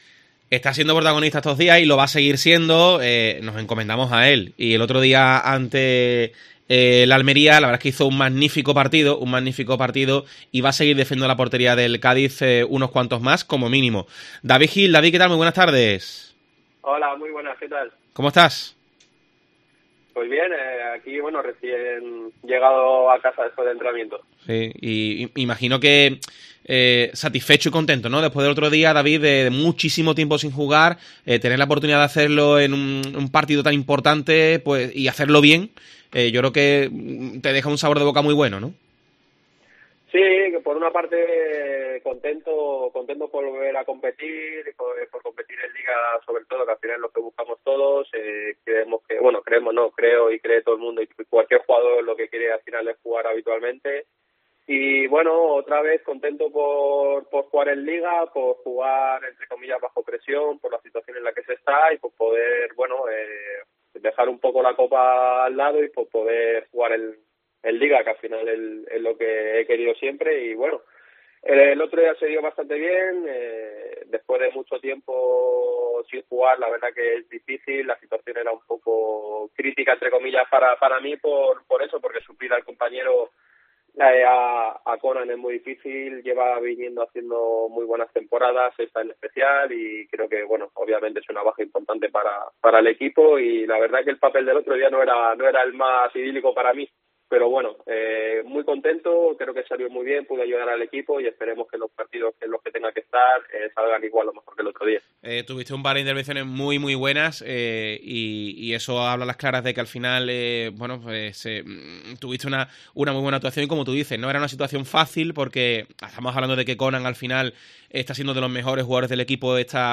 ESCUCHA LA ENTREVISTA AL PORTERO DEL CÁDIZ CF DAVID GIL